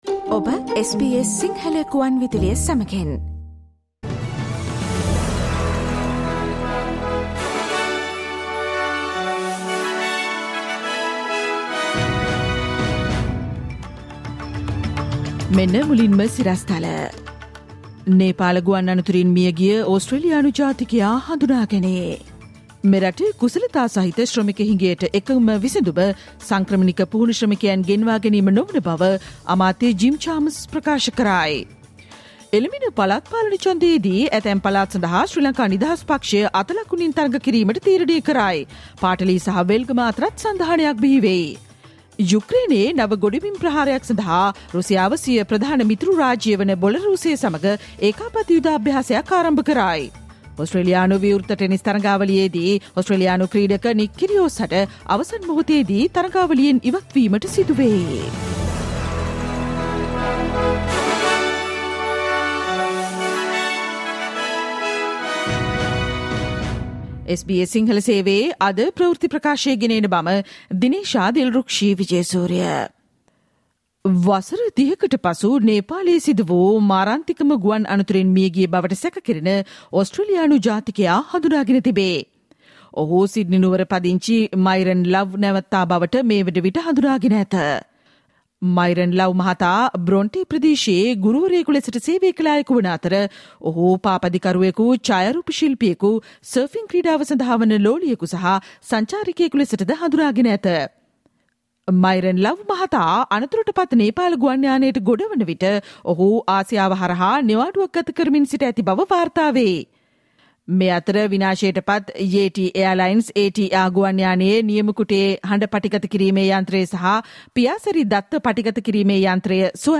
Listen to the SBS Sinhala Radio news bulletin on Tuesday 17 January 2022